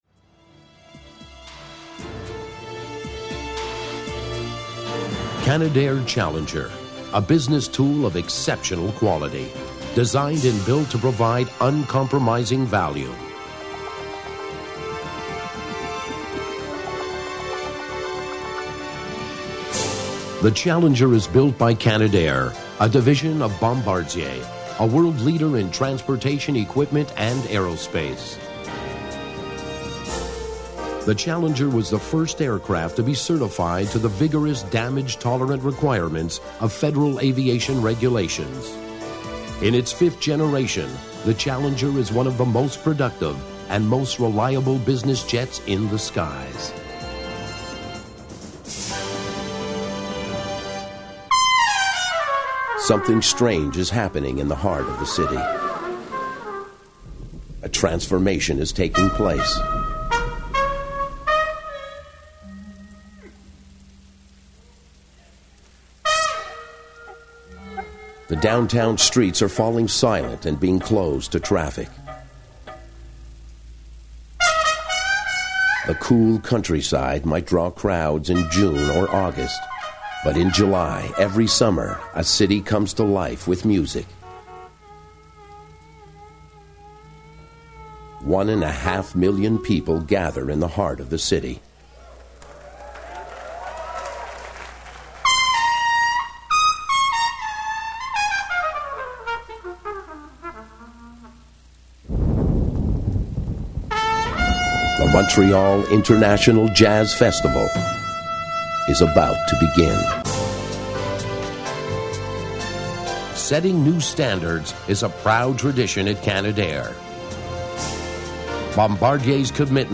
deep bass versatile funny
Sprechprobe: Industrie (Muttersprache):